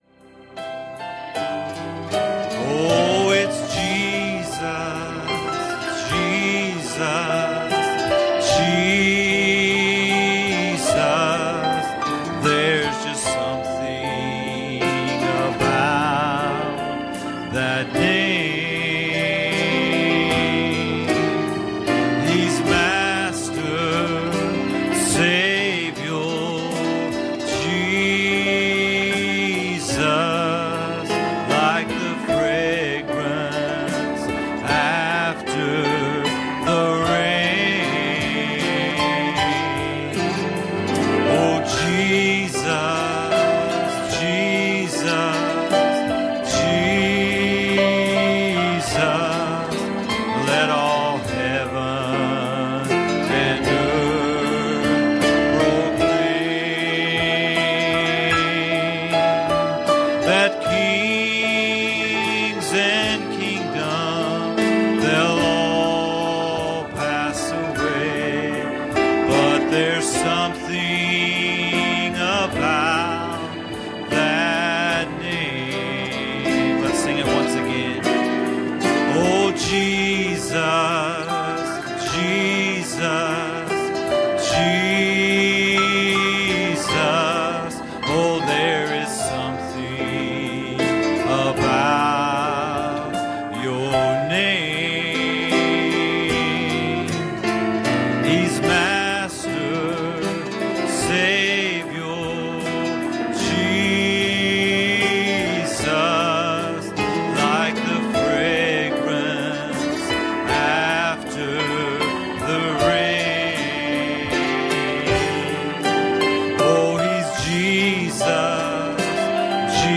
Service Type: Special Service